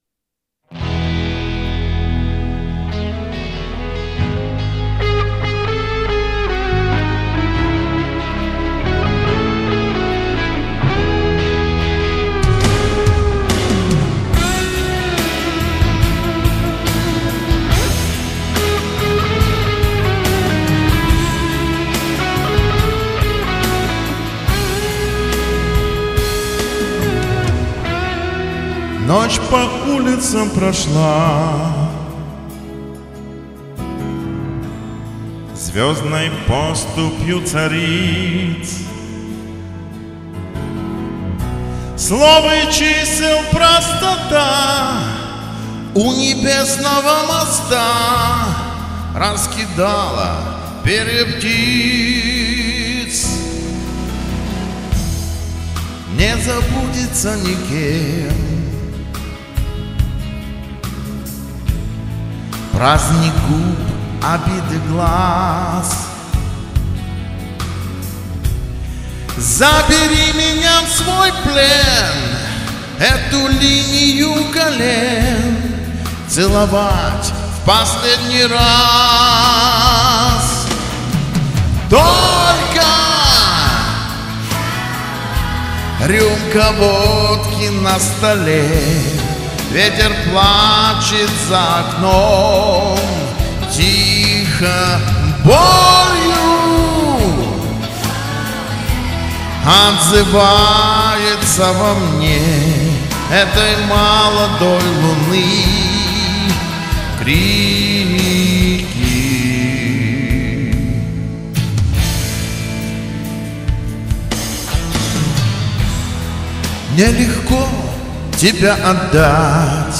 Мне показалось, что у Рюмки не удался припев.